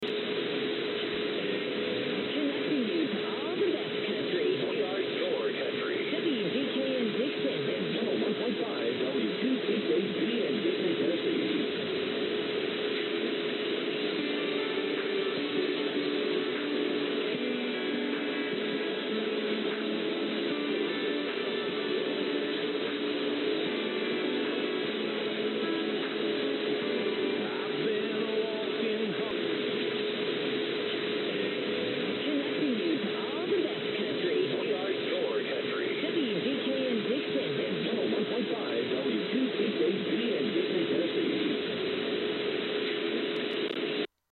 Listening to radio stations from the Americas
The station booming in from nowhere with country and a perfect TOH ID that you can hear on the enclosed mp3.